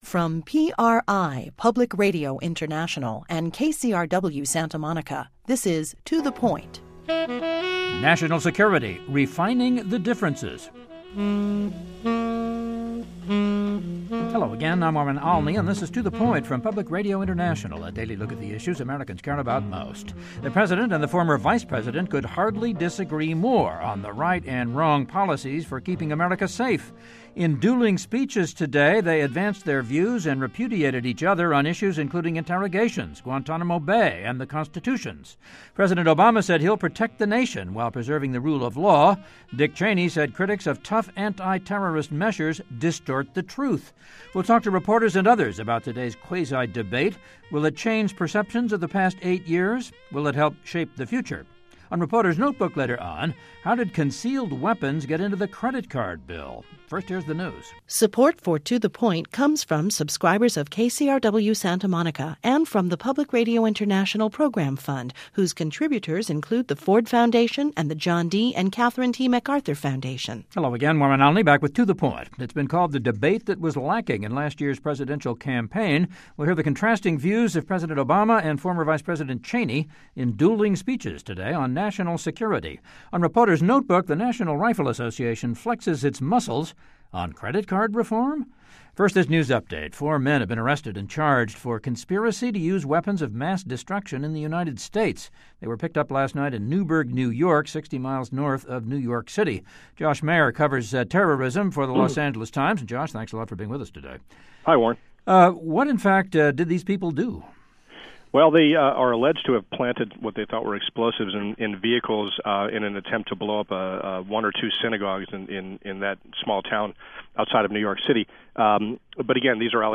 We hear the contrasting views of President Obama and former Vice President Cheney in dueling speeches today on national security. Also, four arrests in the New York conspiracy to use MWD against synagogues and US military aircraft, the National Rifle Association flexes its muscles--on credit card reform.